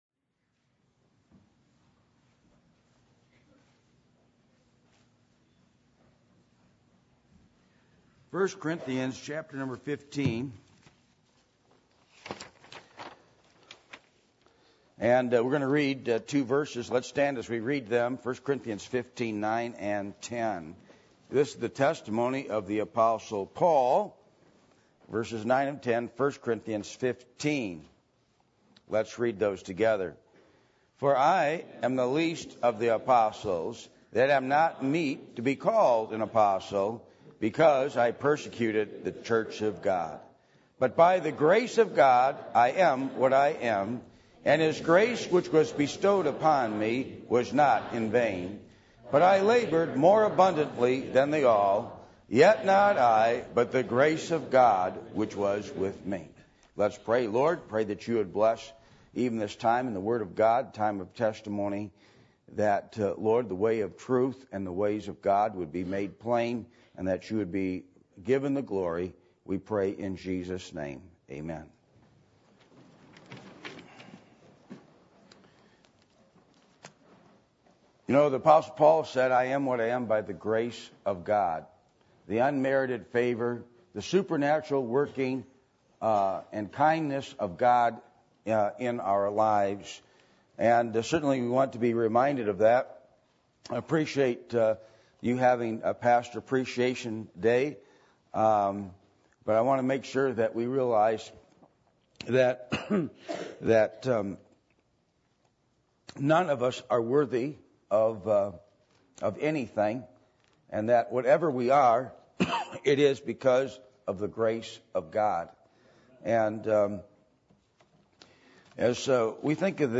1 Corinthians 15:9-10 Service Type: Sunday Evening %todo_render% « The Four Alleluia’s Of Revelation 19 Prayer That’s Heard